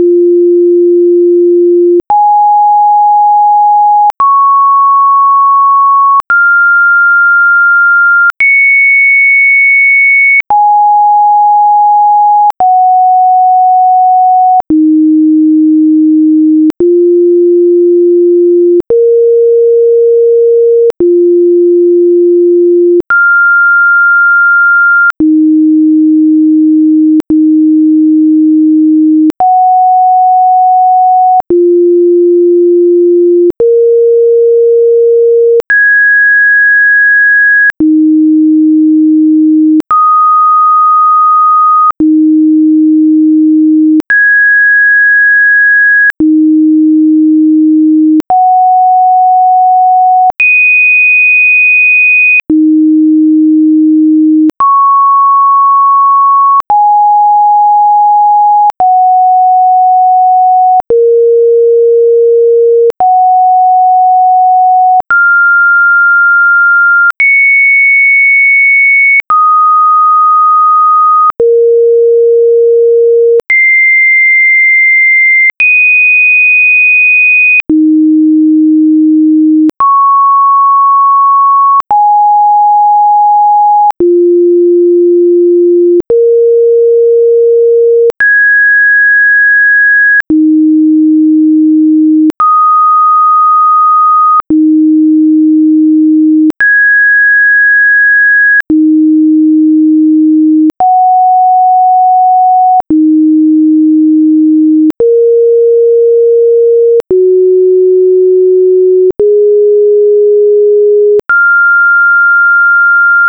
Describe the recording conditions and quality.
ATTENTION! This is an audio puzzle. Be sure to TURN DOWN THE VOLUME on your speakers BEFORE playing the audio.